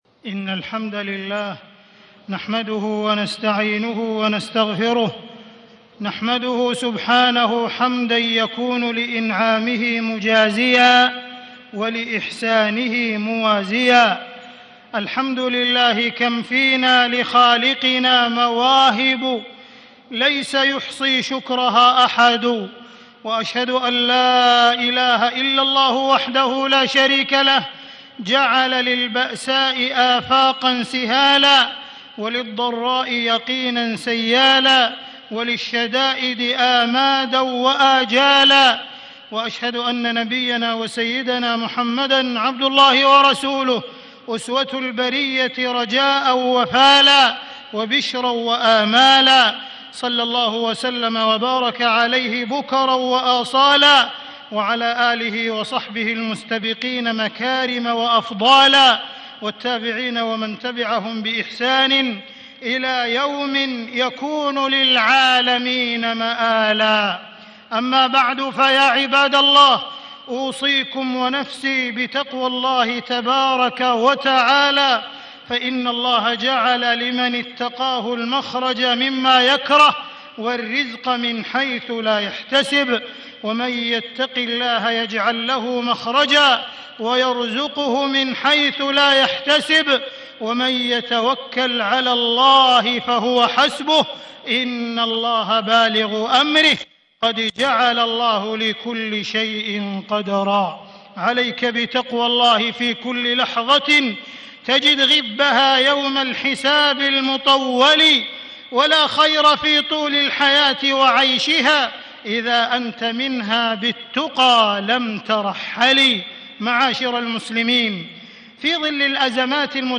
تاريخ النشر ٤ شعبان ١٤٣٦ هـ المكان: المسجد الحرام الشيخ: معالي الشيخ أ.د. عبدالرحمن بن عبدالعزيز السديس معالي الشيخ أ.د. عبدالرحمن بن عبدالعزيز السديس التفاؤل والاستبشار بالنصر